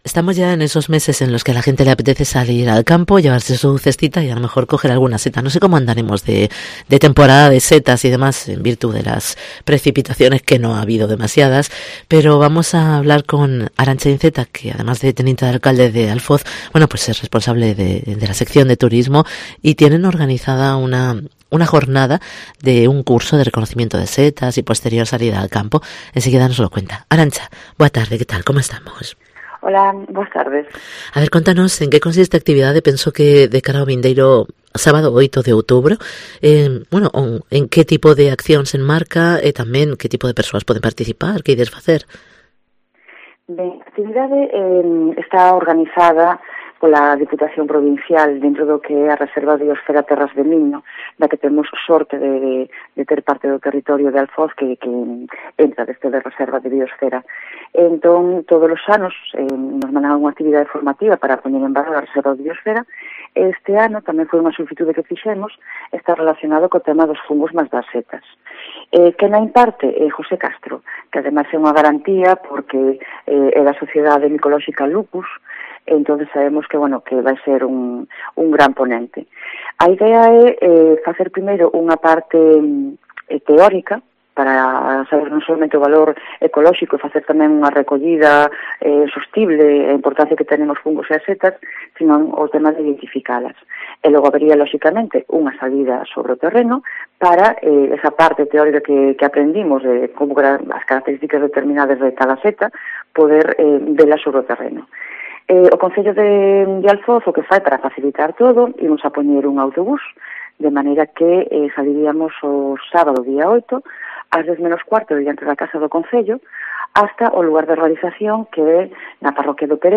Entrevista con Arancha Incera, teniente de alcalde y concejal de Turismo de Alfoz